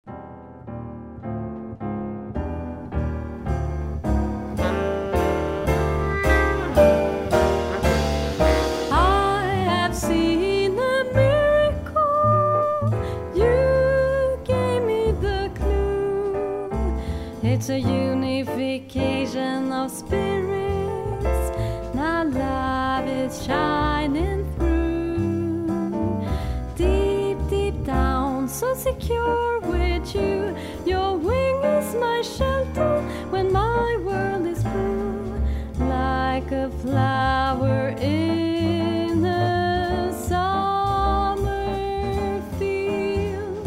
Vocal solo and Organ     Download FREE leadsheet!
NB! Mp3 file is the jazz version